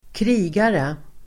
Uttal: [²kr'i:gare]